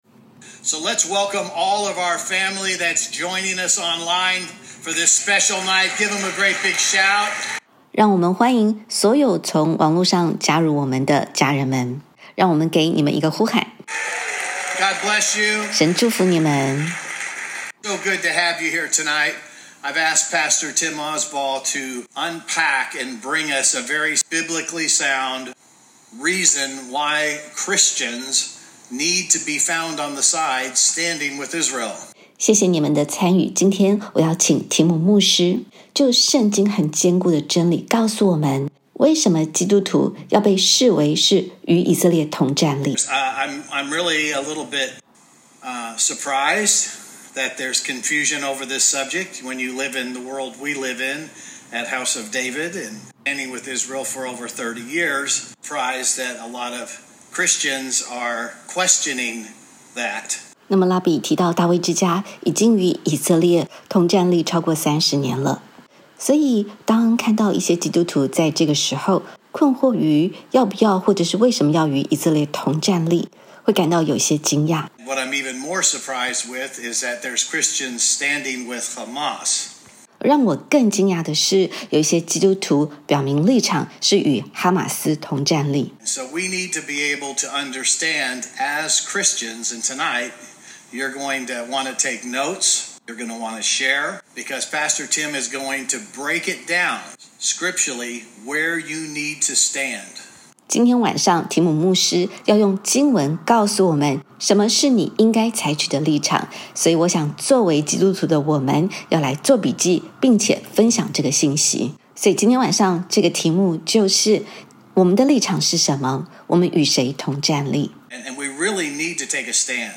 本篇感谢大卫之家 允诺口译并授权微牧刊登